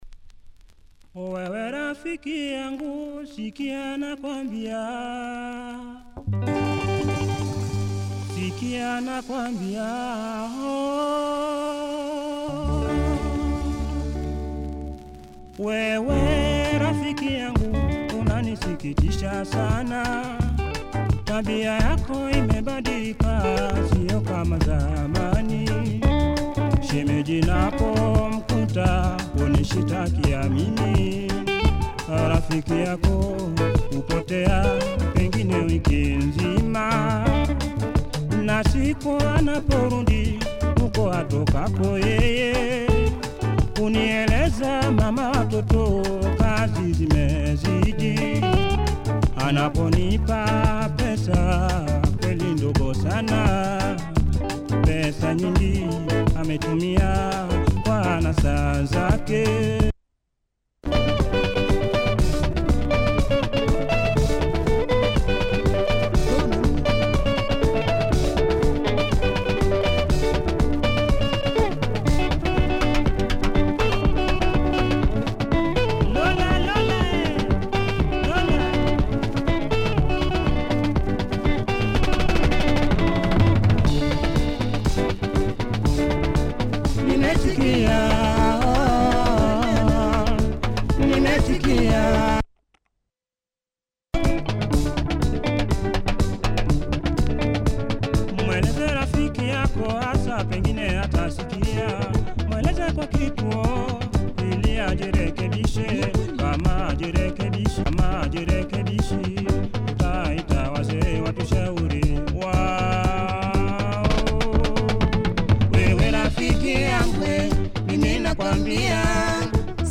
The party starts midway.